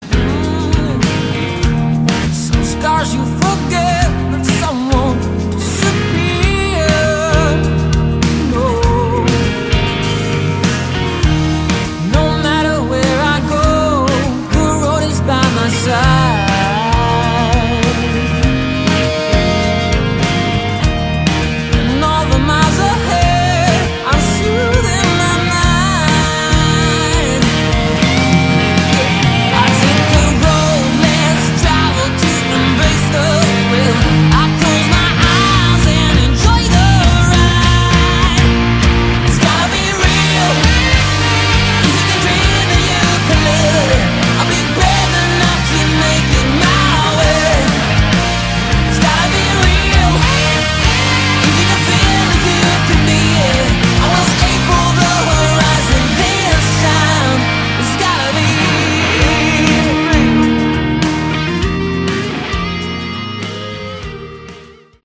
Category: Hard Rock
lead vocals
lead guitars
rhythm guitar
bass
drums